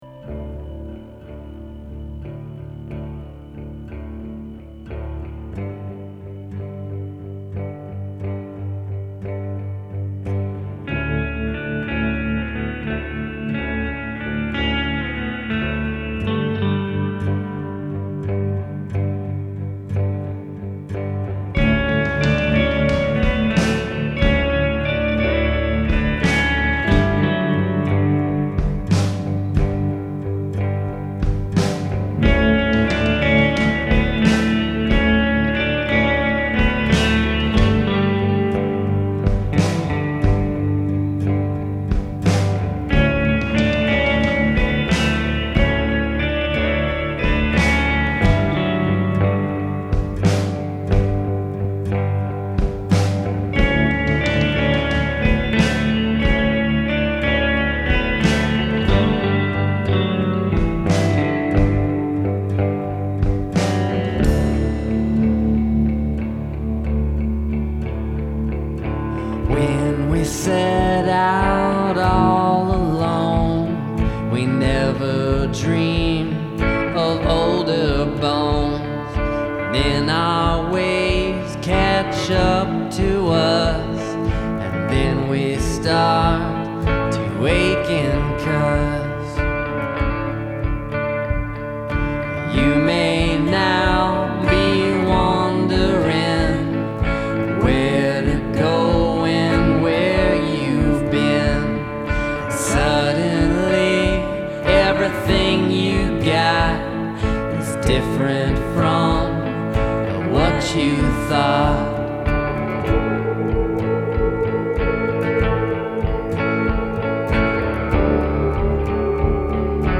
is full of slow paced, wistful songs.